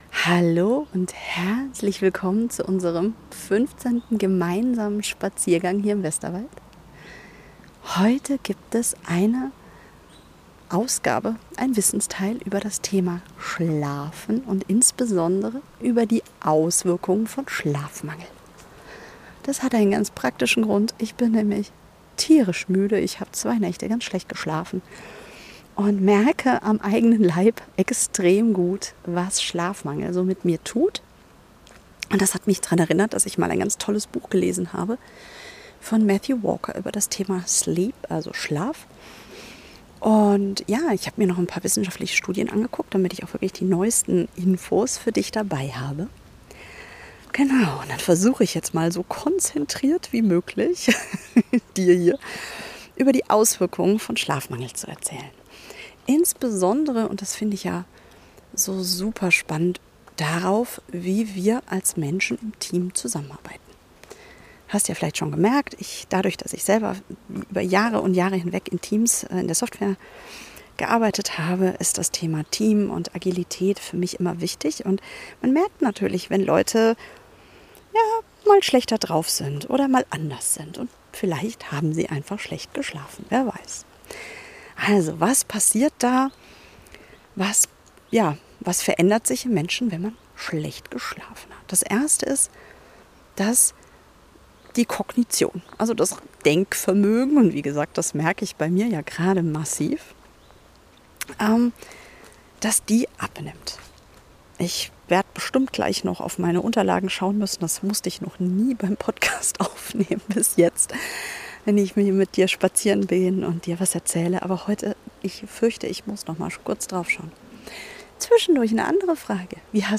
Spaziergang 15.